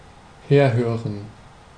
Ääntäminen
Synonyymit mind note hear give ear hark heed list pay attention await anticipate expect wait for obey agree assent attend behear listen to listen in Ääntäminen UK US Tuntematon aksentti: IPA : /ˈlɪs.ən/ IPA : /ˈlɪs.n̩/